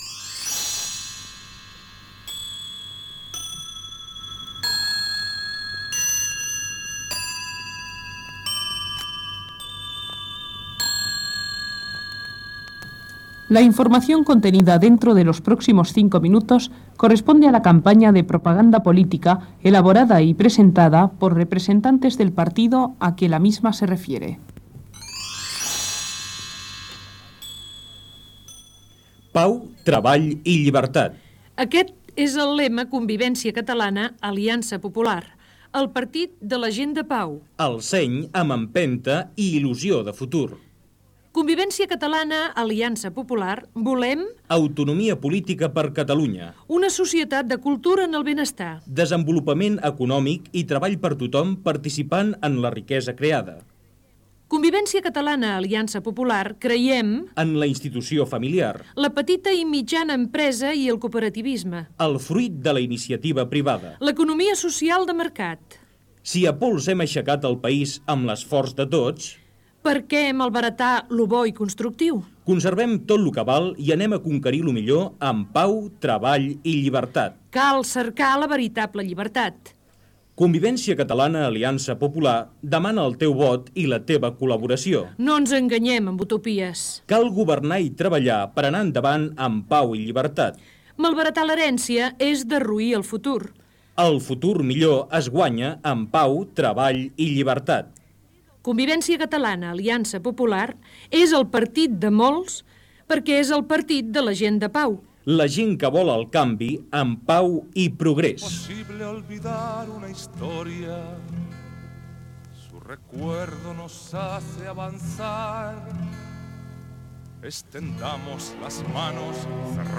Campanya de Propaganda Política: Convergència Catalana -Alianza Popular